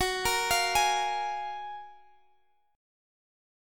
F#M9 chord